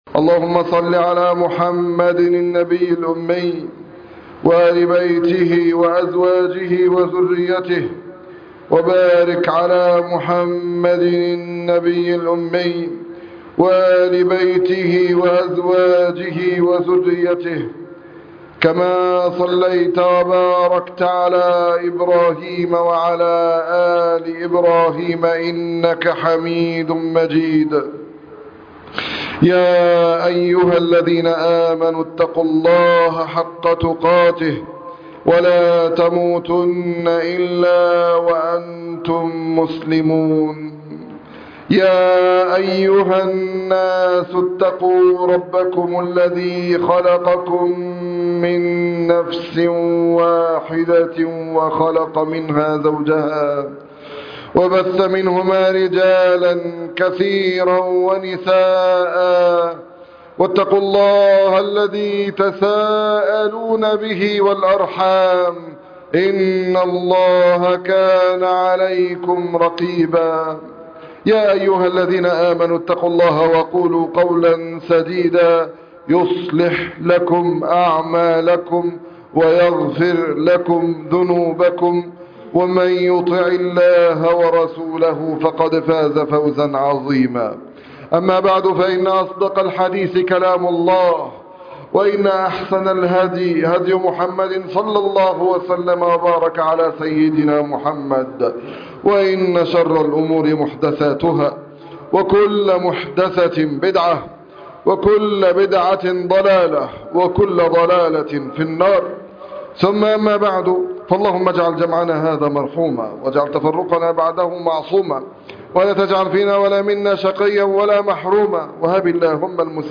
هتعرف تمشي زي هاجر؟! خطبة الجمعة